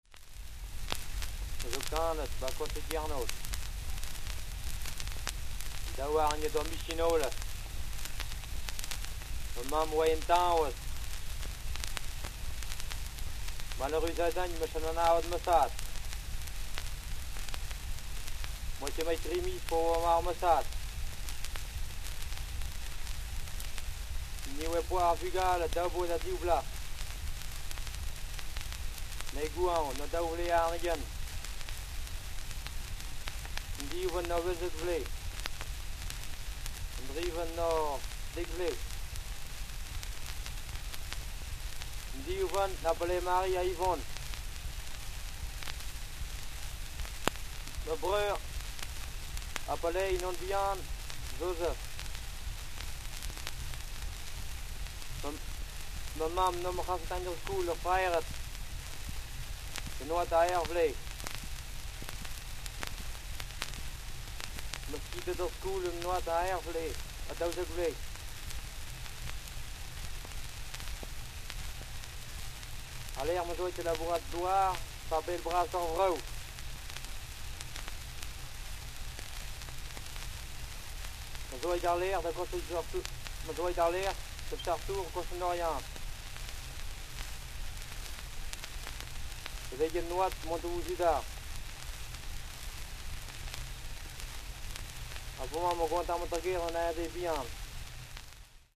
Die Visualisierung der 1,39 Minuten langen Tonaufnahme zeigt längere Sprechpausen.
Schellack-Schallplatte PK 457-1
Auffällig sind wiederkehrende längere Pausen.
Störungen waren vorprogrammiert, denn der Sprecher hatte Schwierigkeiten, der Flüsterstimme zu folgen. Die Aufnahme enthält „Verhaspler“ – wie das Beispiel der Übersetzung oben zeigt –, der Sprecher bleibt „hängen“.